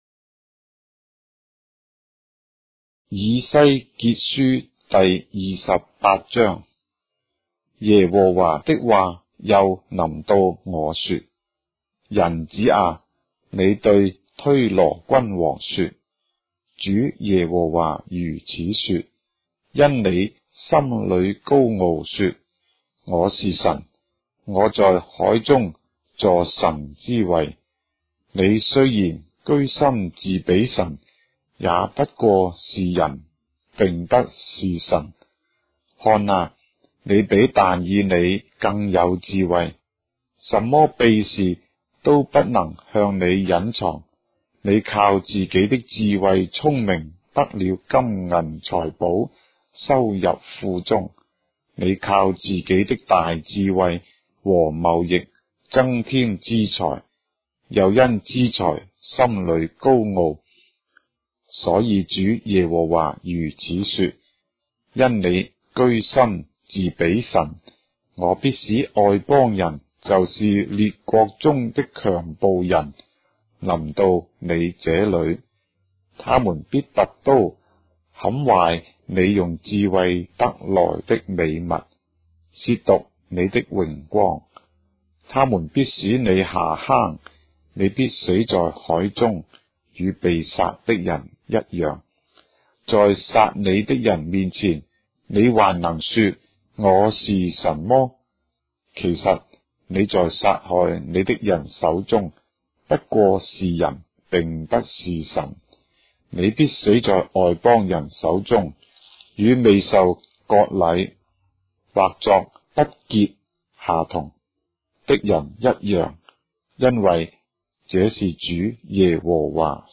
章的聖經在中國的語言，音頻旁白- Ezekiel, chapter 28 of the Holy Bible in Traditional Chinese